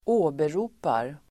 Uttal: [²'å:bero:par]